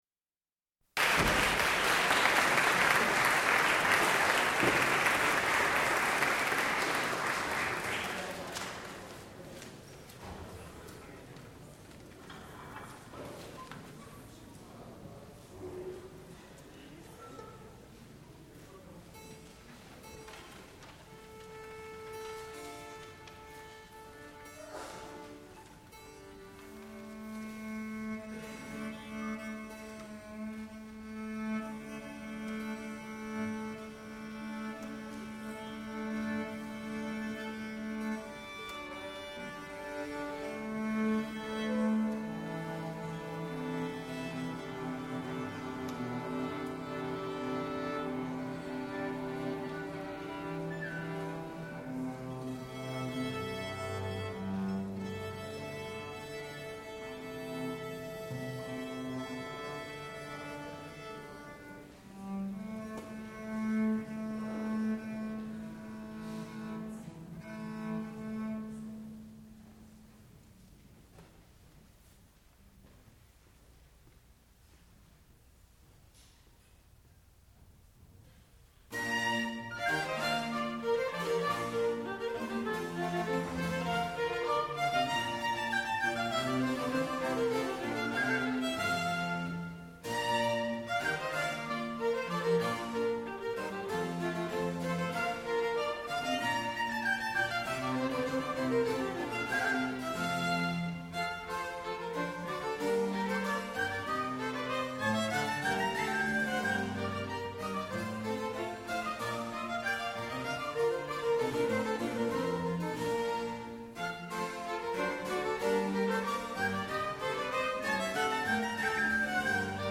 sound recording-musical
classical music
baritone
contrabass
harpsichord